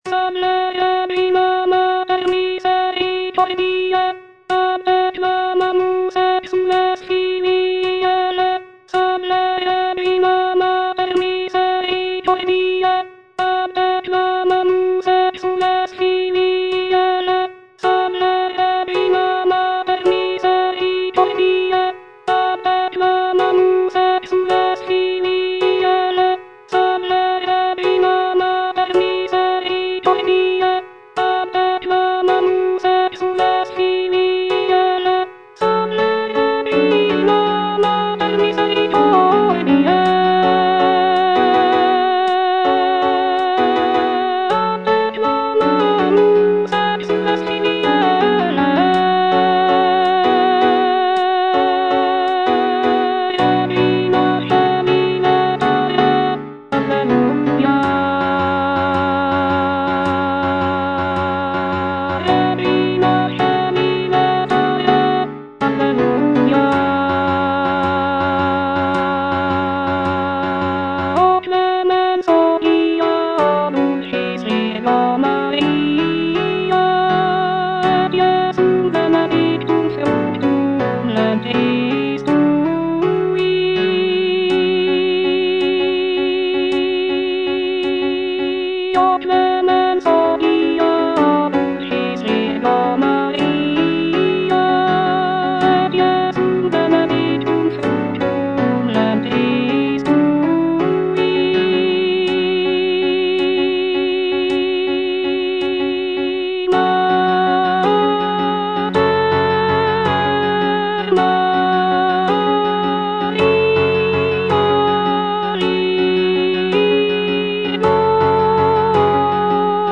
Alto II (Voice with metronome) Ads stop